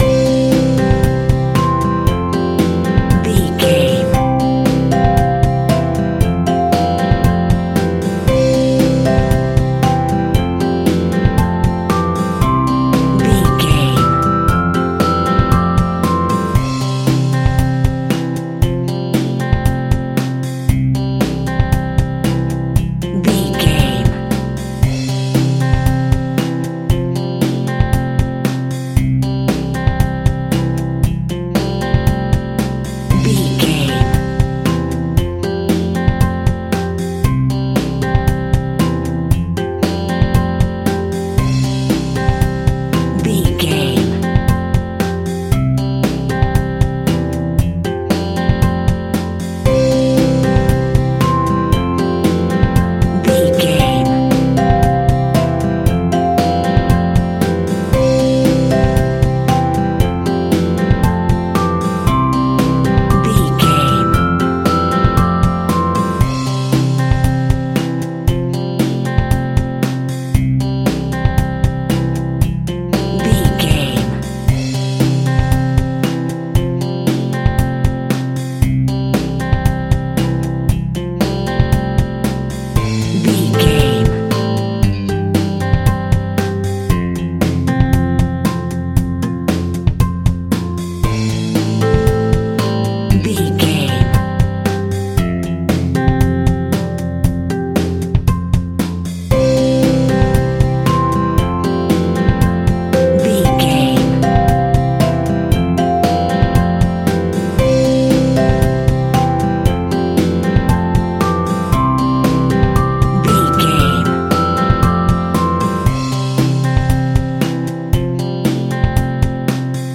Fresh Playful Pop Music.
Ionian/Major
pop rock
indie pop
fun
energetic
uplifting
synths
drums
bass
guitar
piano